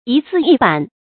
一字一板 注音： ㄧ ㄗㄧˋ ㄧ ㄅㄢˇ 讀音讀法： 意思解釋： 形容說話從容清楚。